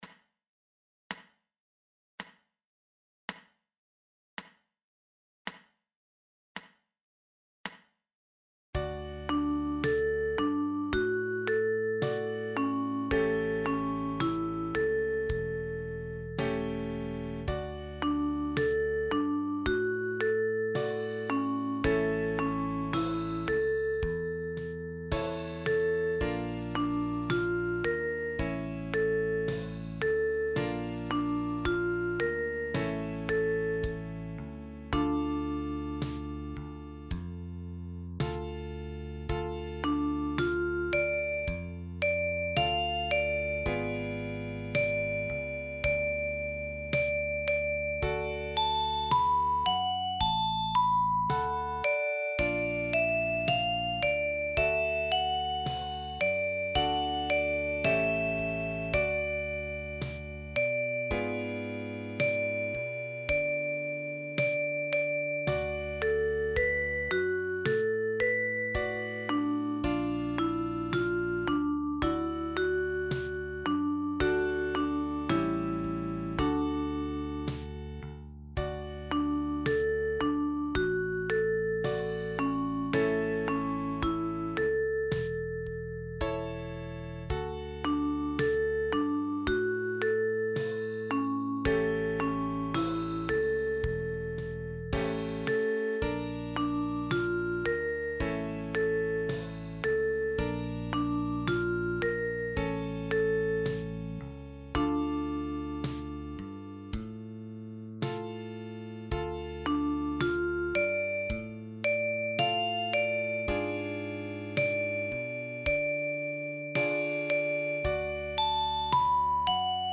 notiert für die chromatische Mundharmonika